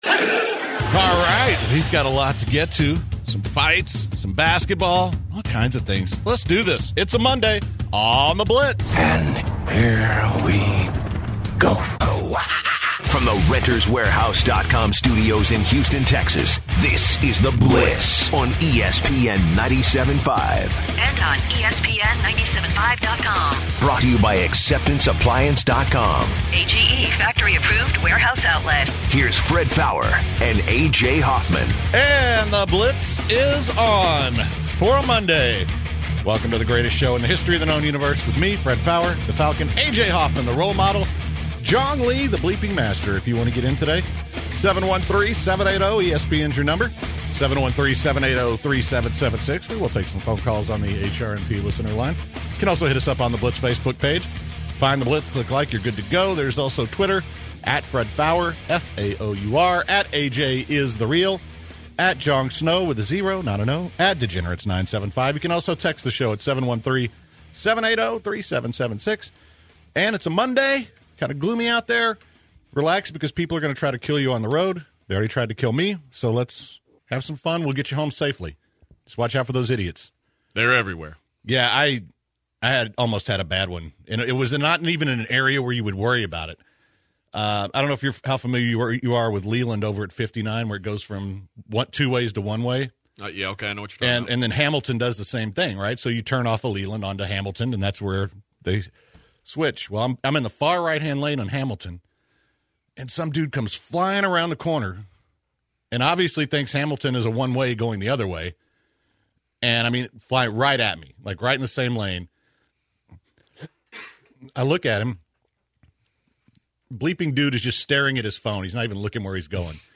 The guys then debate the current state of the Kings and Pelicans and take some great calls on the trade . The guys end the show with a some MMA talk from the fights over the weekend.